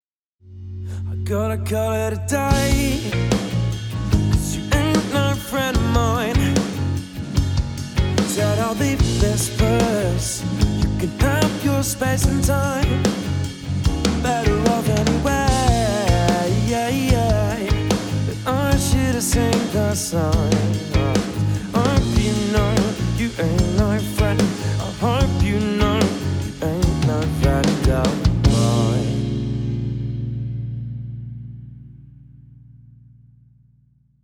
HPF 30 Hz
LF boost at 90 Hz
HF boost at 8 kHz
LMF Narrow Cut (FOCUS OUT) at 300 Hz
HMF Wide Boost (FOCUS OUT) at 2 kHz
UV-EQ Mix.wav